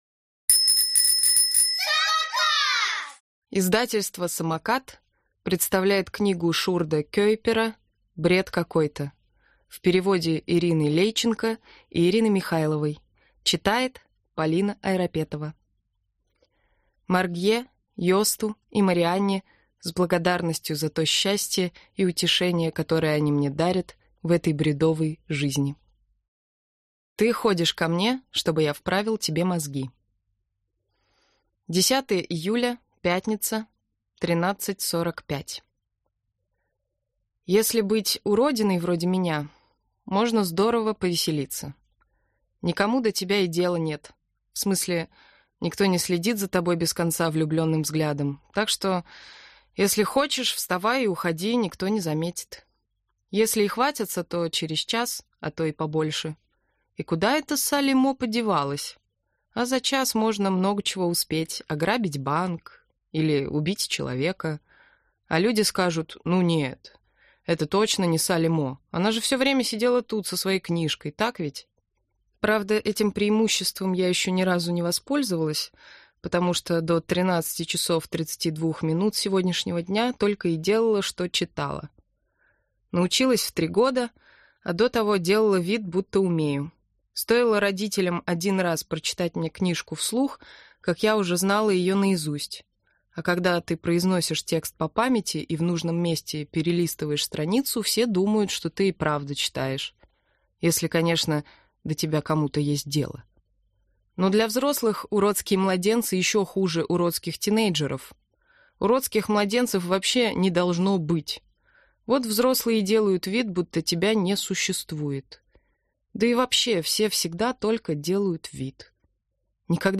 Аудиокнига Бред какой-то!